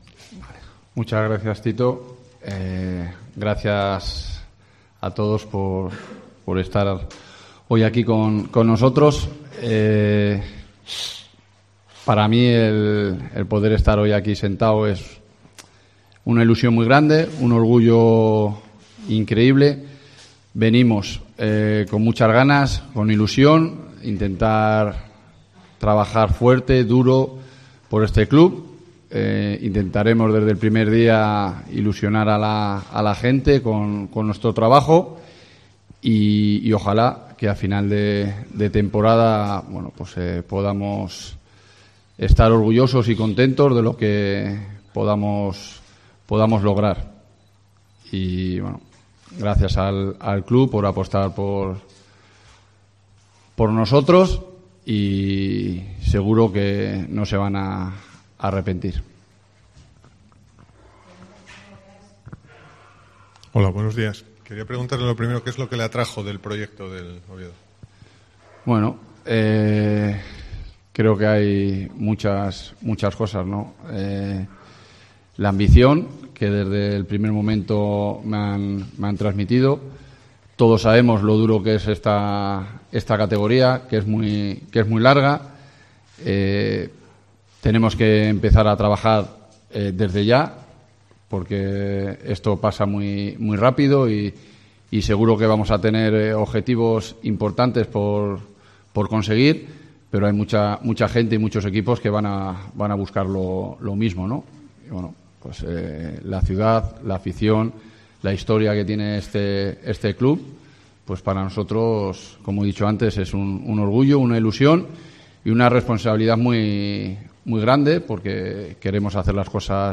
Presentación oficial